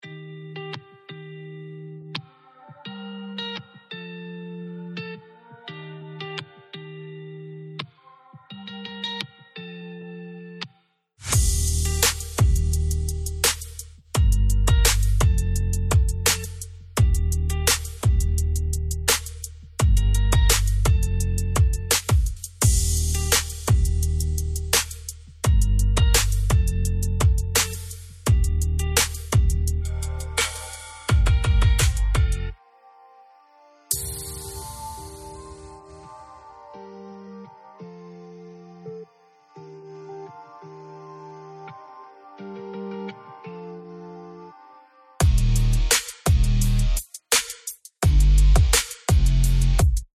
• The Frequency: Ethereal R&B and Soul.